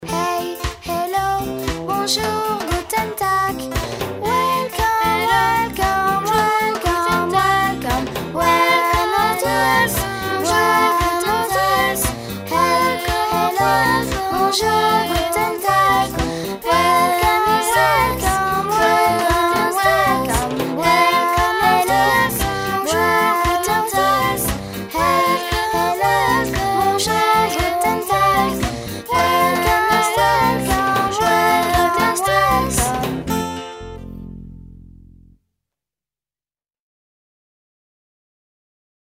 34 Hey, hello - canon 3 voix.mp3